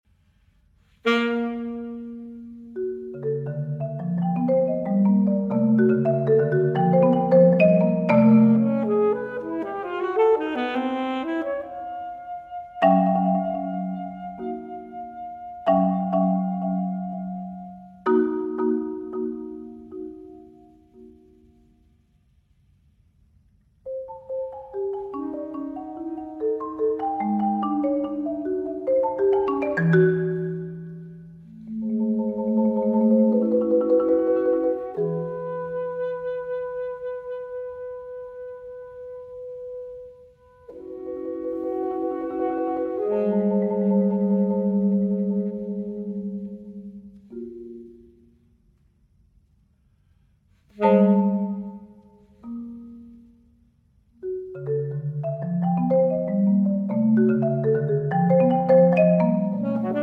saxophone
marimba